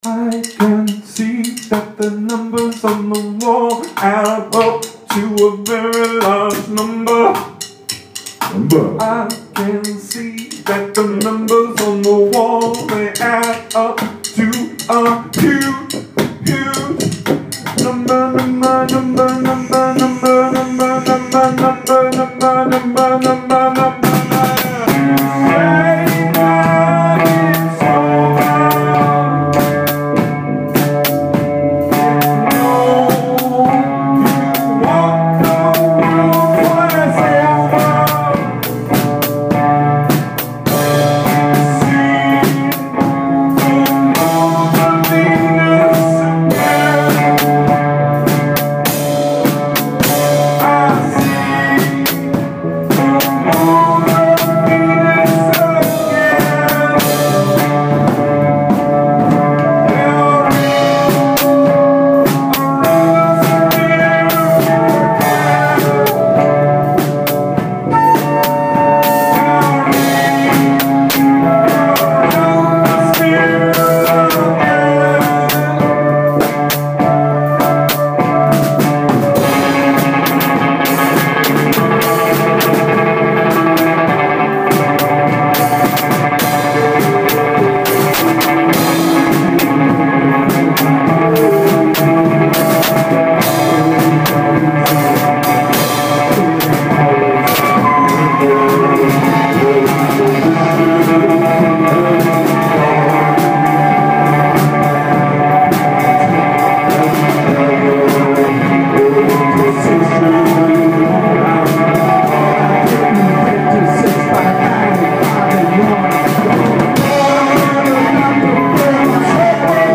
vocal/guitar
drums
bass/voice
flute